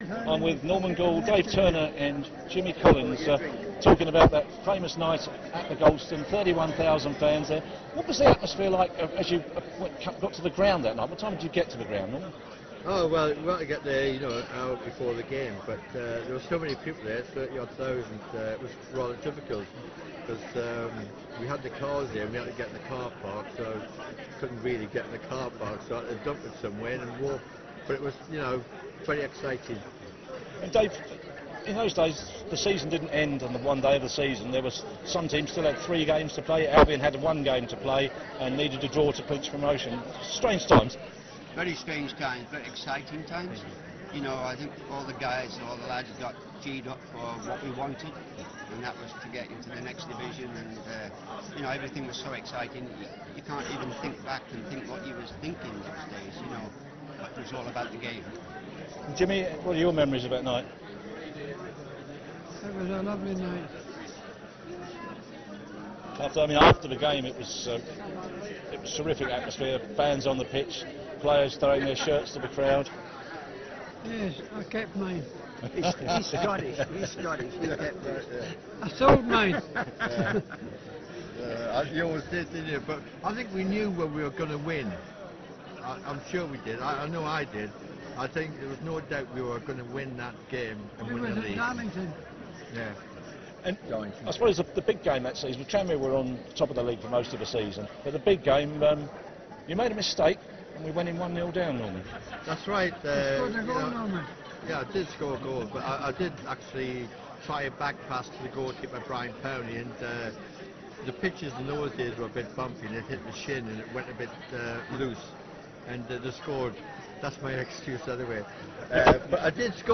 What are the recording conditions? Albion players from 1964/65 season reunite at The Amex